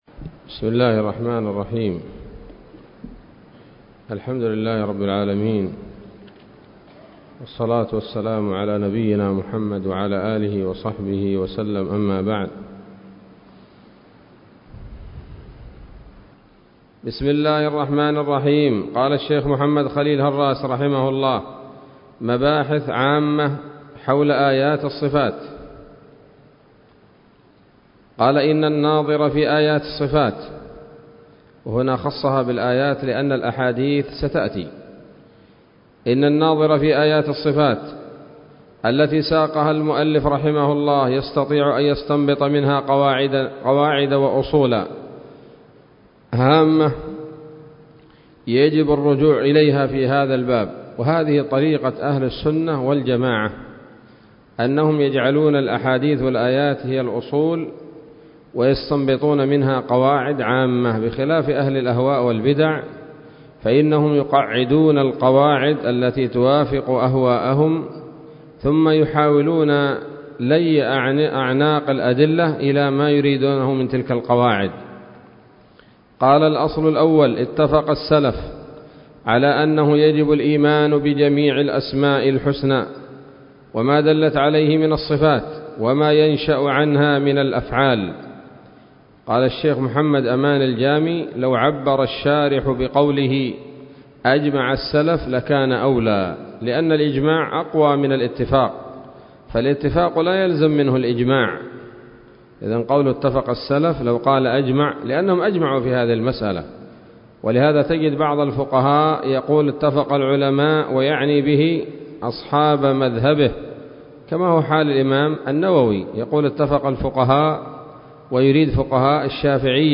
الدرس الثاني والثمانون من شرح العقيدة الواسطية للهراس